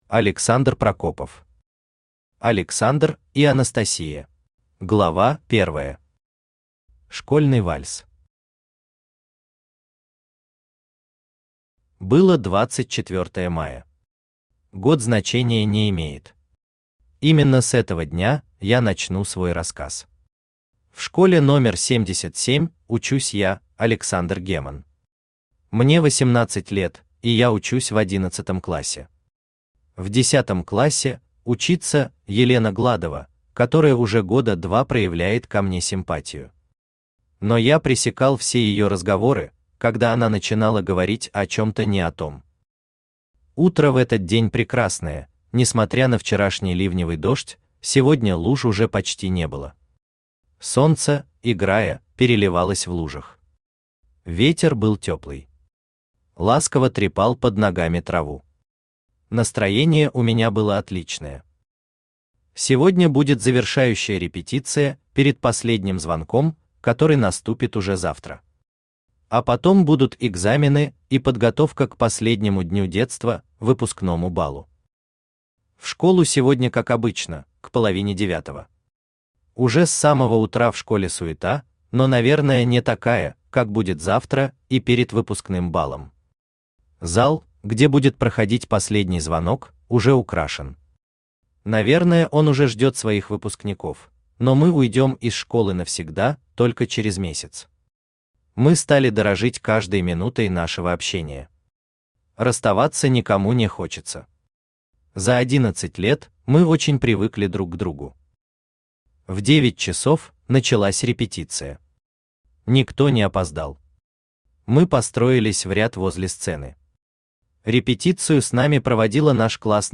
Аудиокнига Александр и Анастасия | Библиотека аудиокниг
Aудиокнига Александр и Анастасия Автор Александр Прокопов Читает аудиокнигу Авточтец ЛитРес.